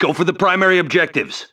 、 分类:维和步兵语音 您不可以覆盖此文件。